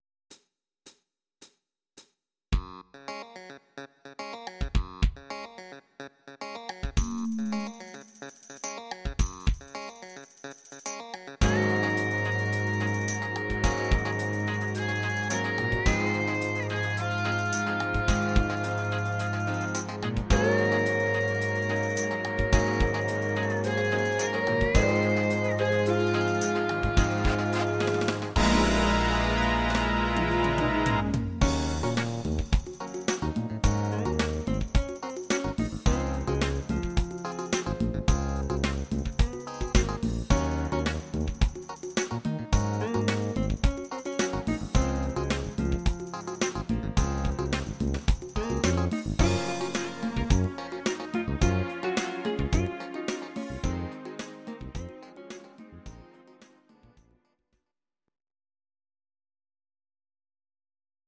Audio Recordings based on Midi-files
Pop, Musical/Film/TV, 1970s